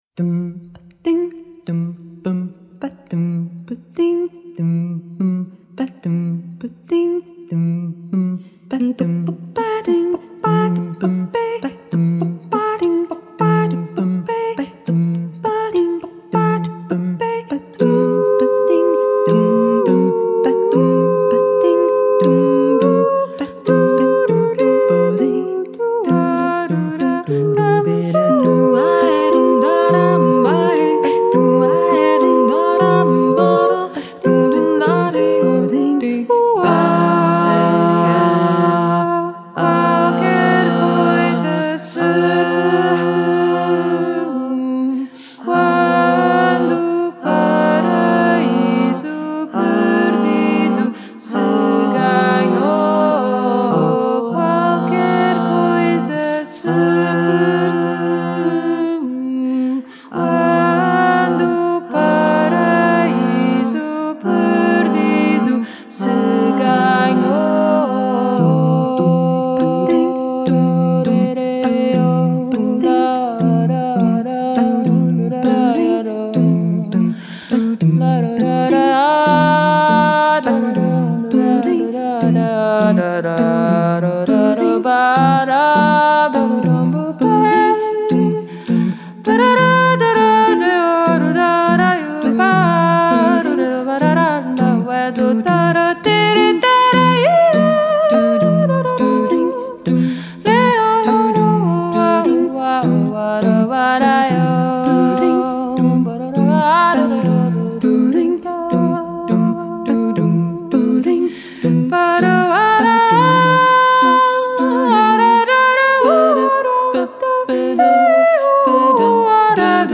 an evocative a cappella vocal quartet
they fashion lush harmonies and strong rhythmic drive
Genre: Jazz, Vocal, A Capella